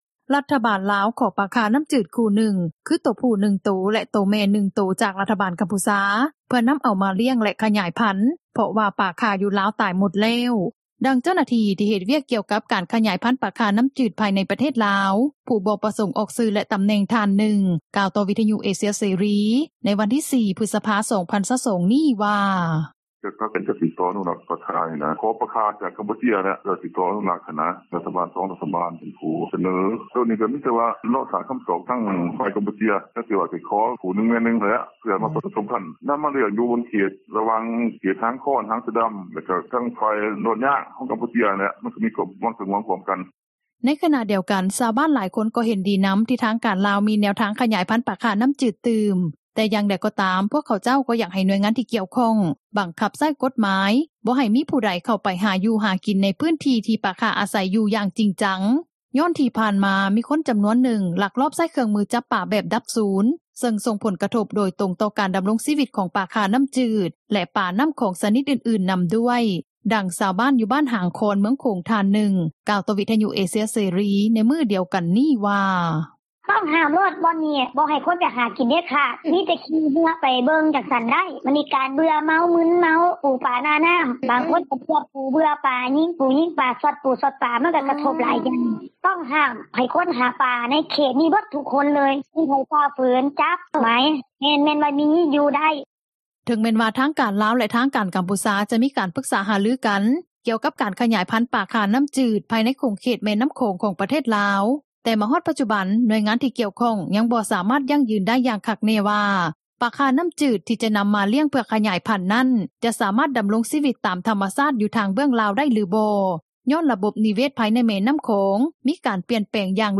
ດັ່ງຊາວບ້ານ ຢູ່ບ້ານຫາງຄອນ ເມືອງໂຂງທ່ານນຶ່ງ ກ່າວຕໍ່ວິທຍຸເອເຊັຽເສຣີ ໃນມື້ດຽວກັນນີ້ວ່າ:
ດັ່ງເຈົ້າໜ້າທີ່ ທີ່ເຮັດວຽກກ່ຽວກັບ ການອະນຸຮັກສັດປ່າ ທ່ານນຶ່ງ ກ່າວວ່າ: